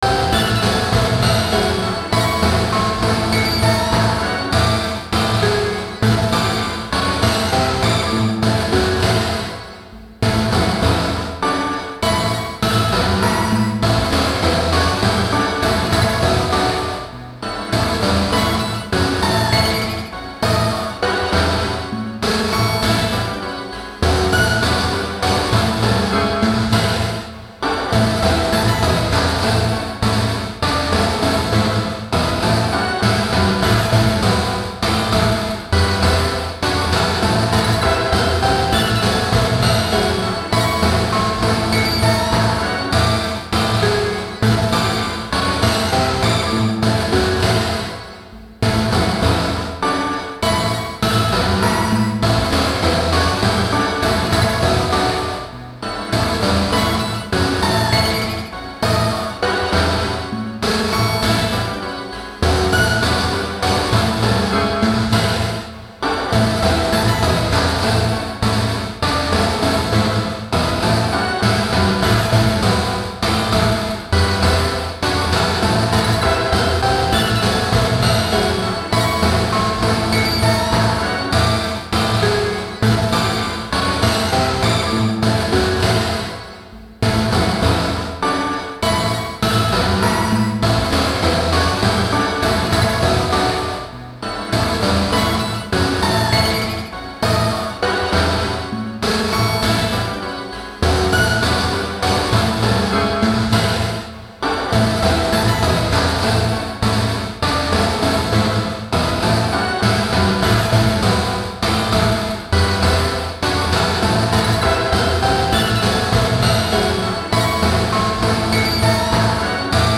こうして多重録音された作品になると、これがギターのみで奏でられているとは思えなくなってしまうのが面白いです。
全体に重量感のあるソリッドなグルーブに支配されたアルバムで、
SiFiムービーや小説などにみられる未来的でドラマチックな終末感を帯びているように感じました。
たいへんデリケートで複雑なパンニングと、スペクトル分布を特徴とするアルバムです。